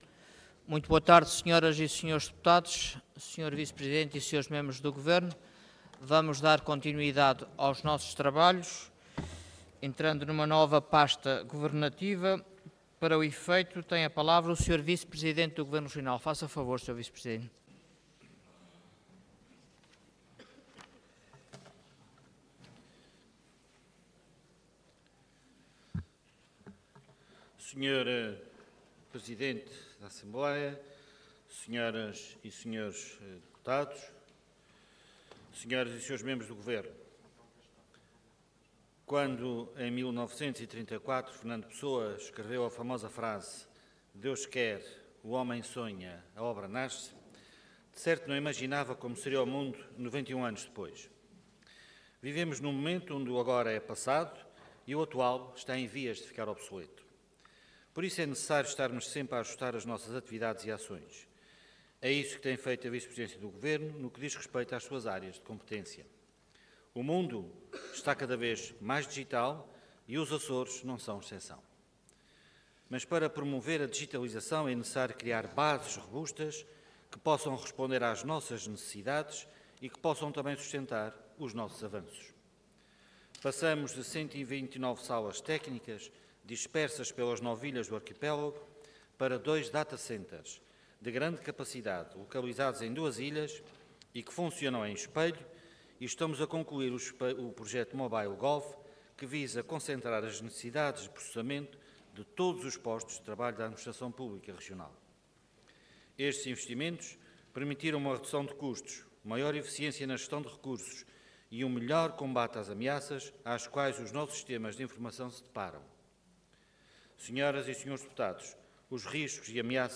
Intervenção de Tribuna Orador Artur Lima Cargo Vice-Presidente do Governo Regional